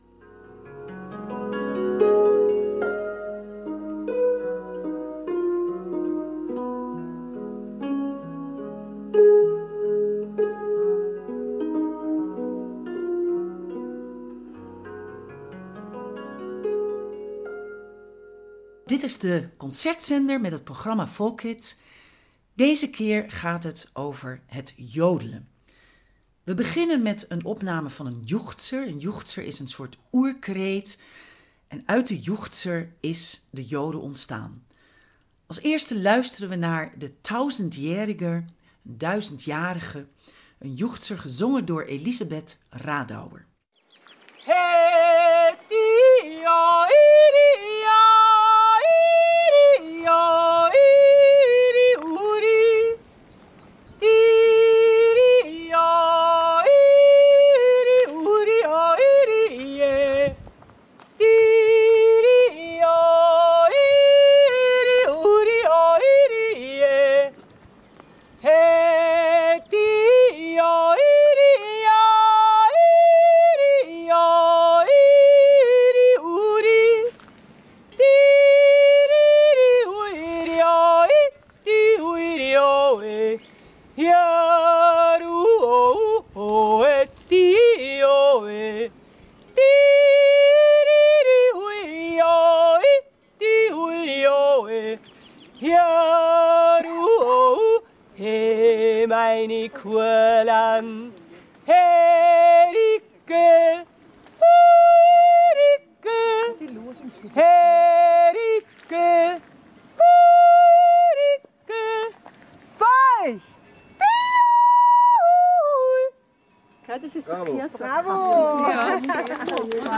Het programma eindigt met musici uit de Alpenlanden die het jodelen verder ontwikkelden en combineerden met andere muziekstijlen.